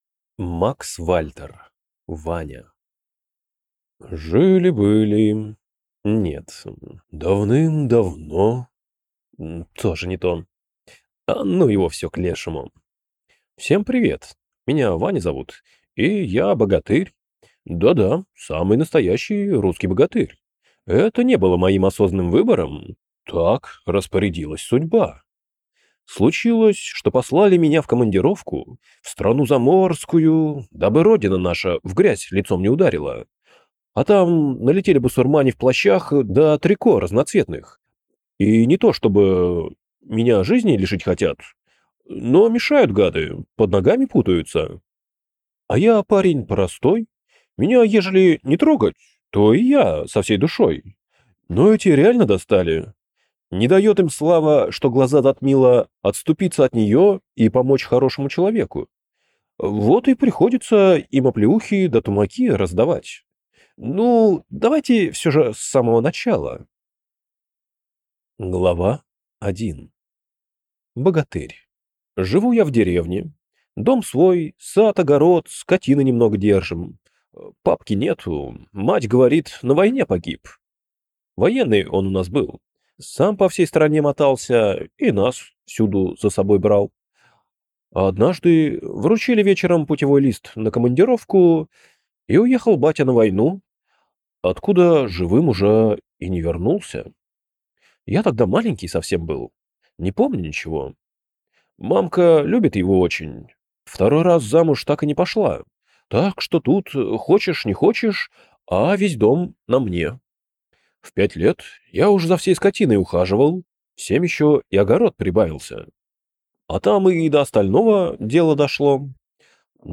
Аудиокнига Ваня | Библиотека аудиокниг